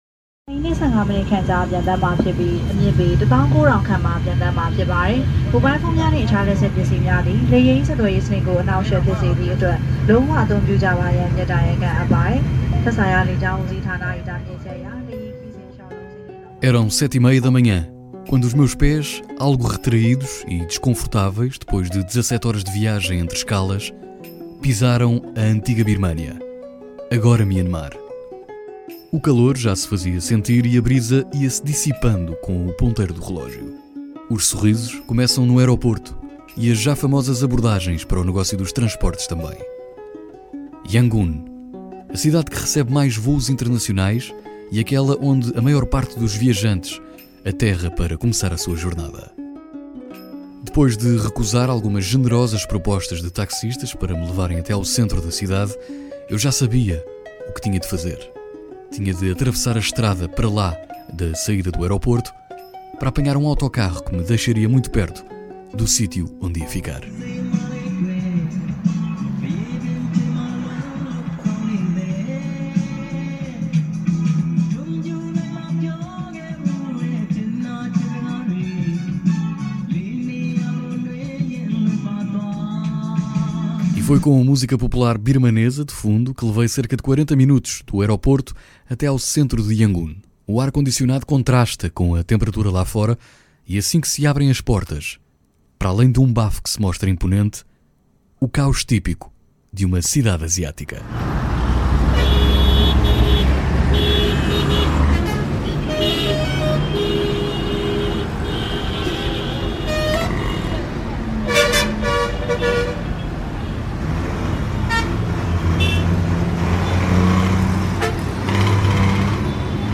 Esta é uma experiência sonora imersiva para descobrir a Birmânia, terra de gente boa.